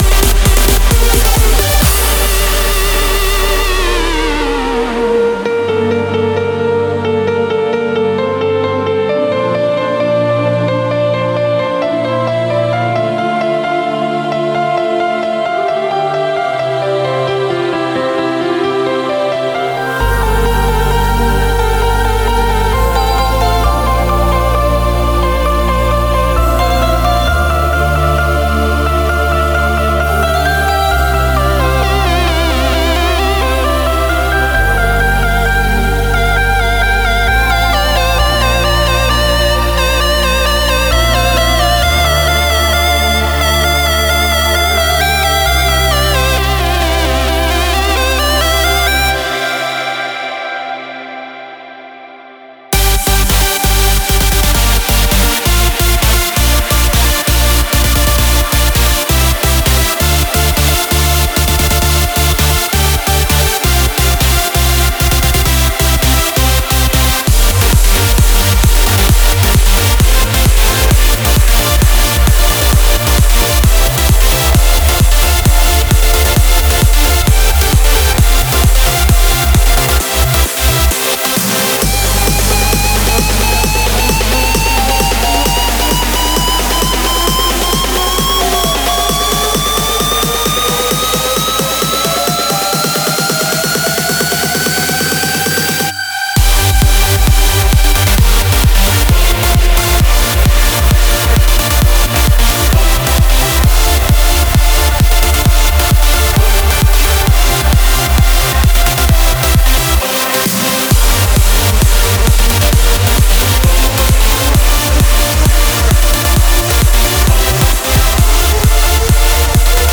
Comments[EDM TRANCE]